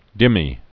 (dĭmē) Islam